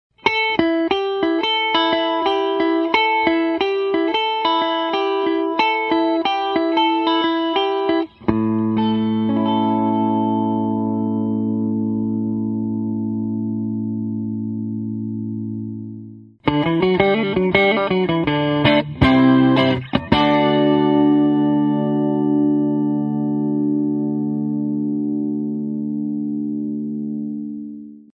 12 string electric with compressor
Mini-switch for release time select, short release time gives that "clack" sound, very good for single notes riffs.
12string-compressor08.mp3